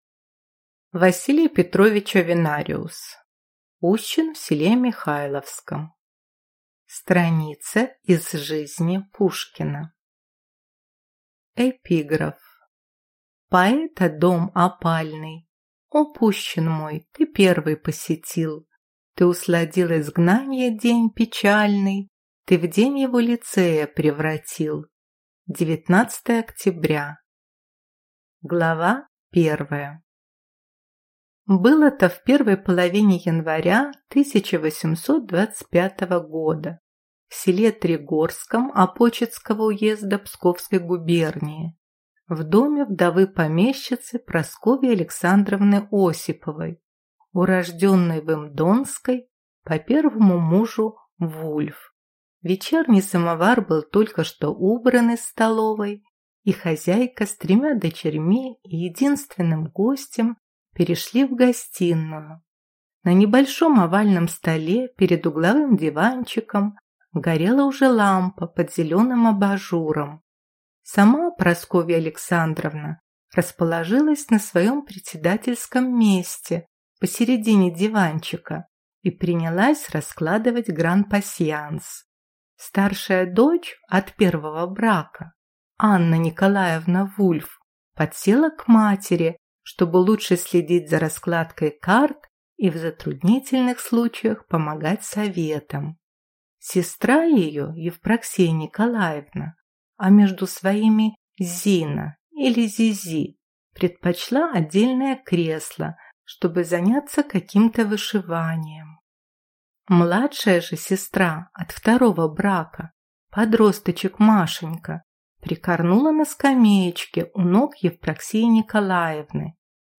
Аудиокнига Пущин в селе Михайловском | Библиотека аудиокниг